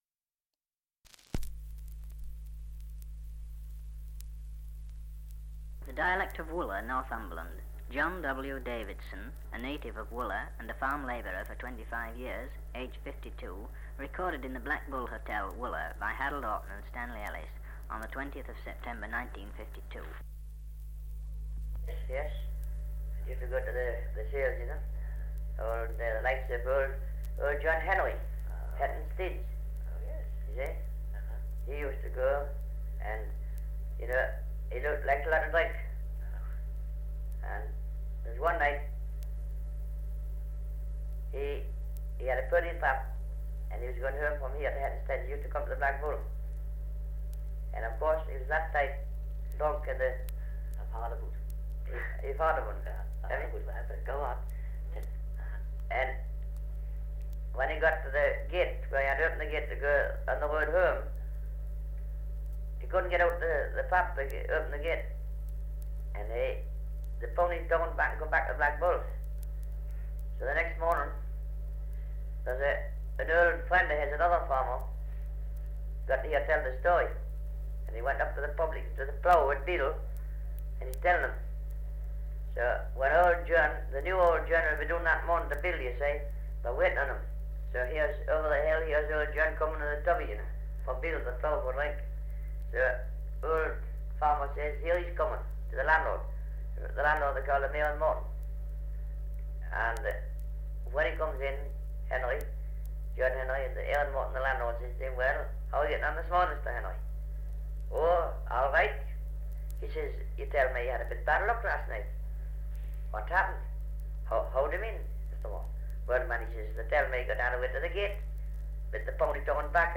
Dialect recording in Newbiggin-by-the-Sea, Northumberland. Dialect recording in Wooler, Northumberland
78 r.p.m., cellulose nitrate on aluminium